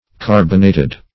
Carbonated \Car"bon*a`ted\